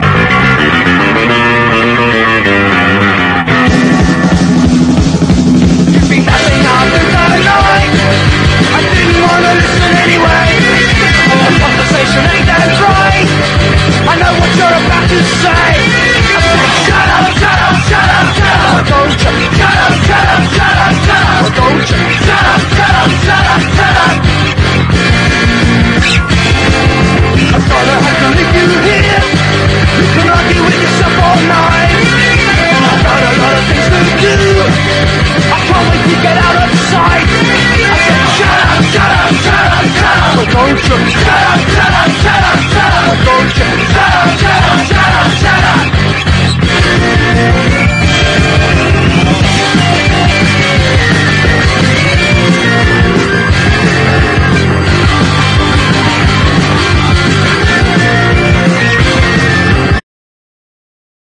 NEW WAVE / DISCO
12インチ・オンリー音源ばかりを収録したミュータント・ディスコ/ロック・ベスト！